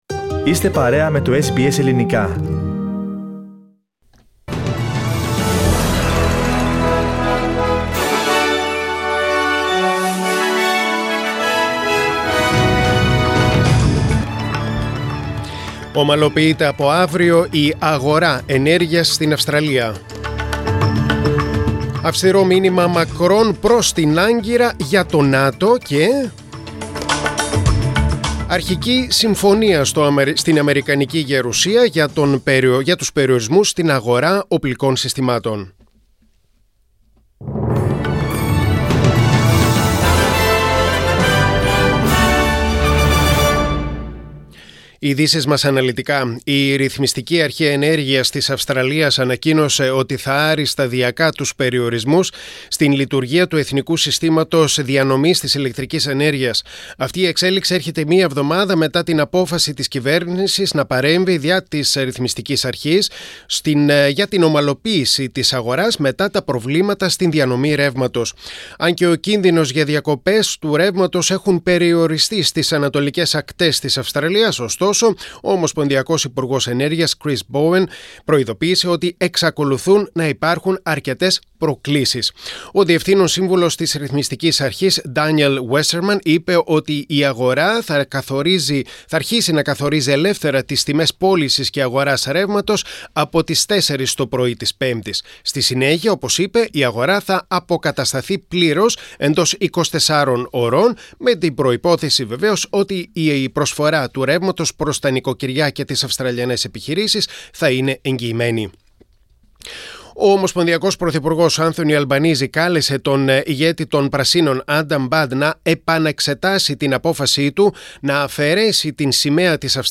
Δελτίο Ειδήσεων: Τετάρτη 22.6.2022